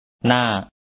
da1y to get, attain, can